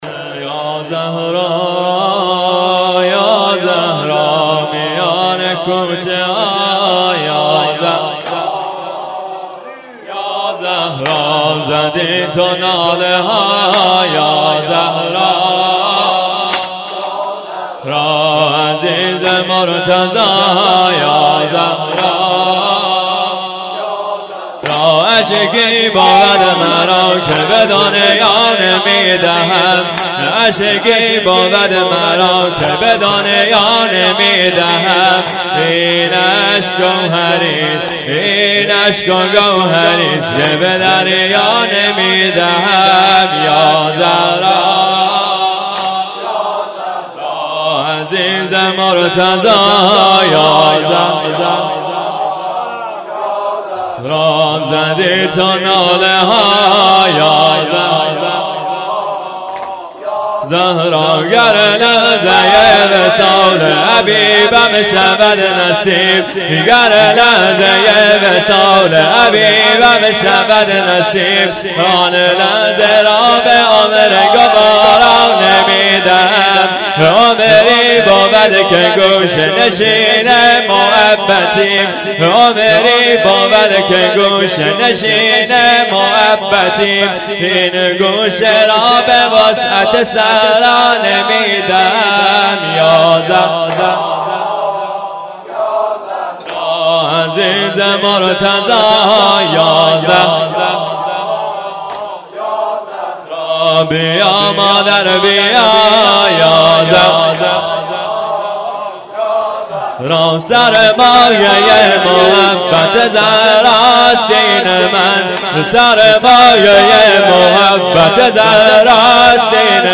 مراسم شب ششم فاطمیه دوم 93/94